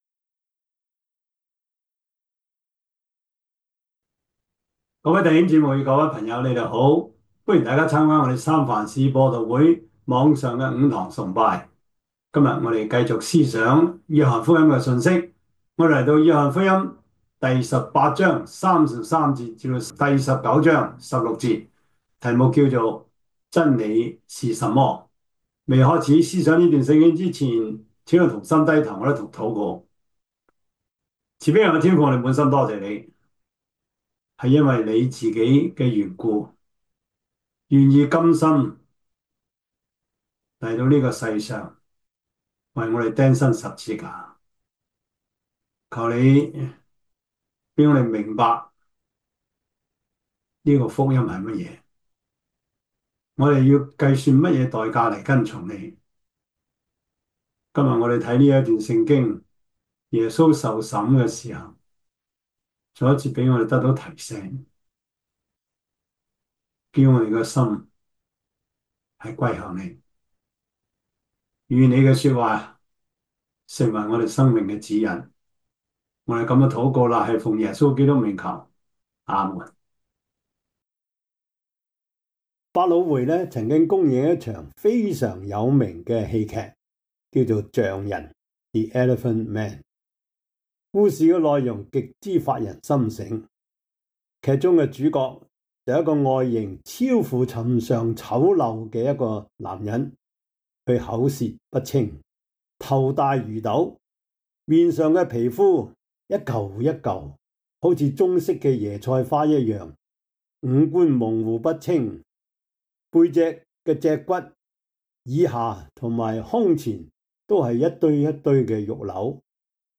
約翰福音 18:33-9:16 Service Type: 主日崇拜 約翰福音 18:33-19:16 Chinese Union Version